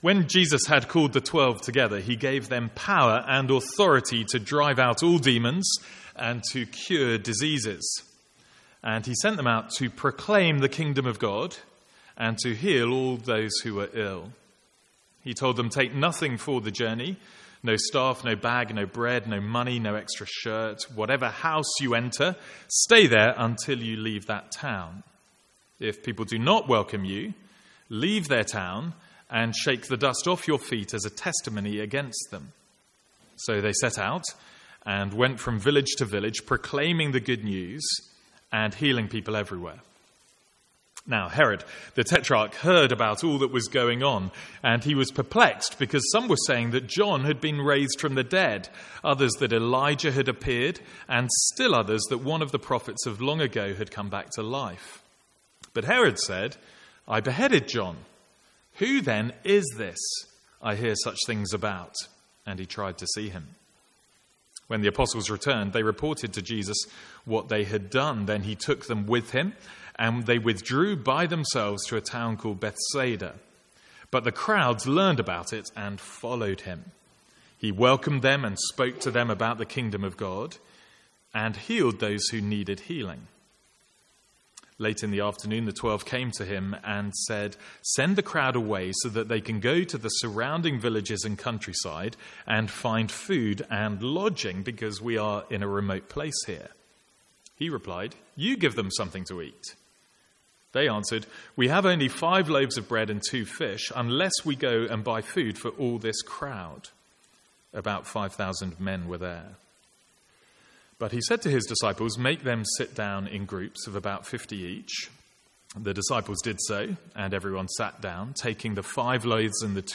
Sermons | St Andrews Free Church
From the Sunday morning series in Luke.